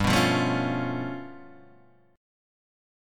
G 9th Flat 5th